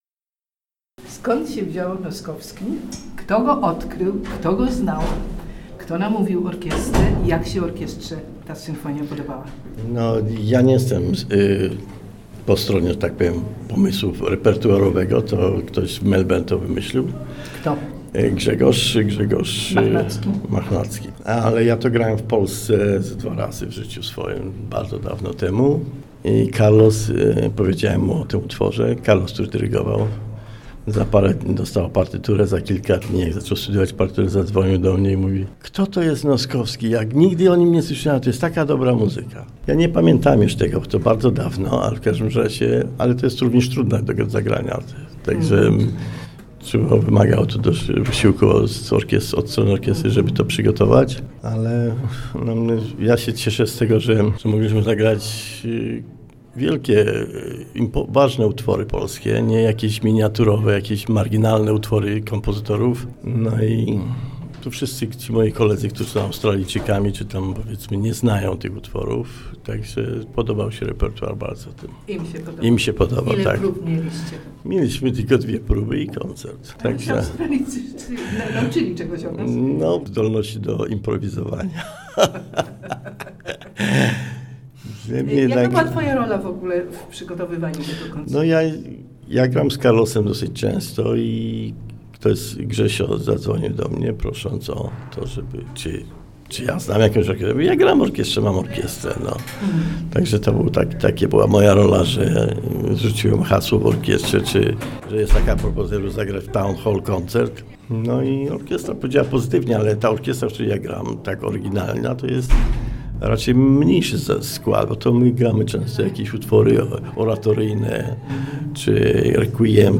Tribute to Freedom w sydnejskim Ratuszu
Członek orkiestry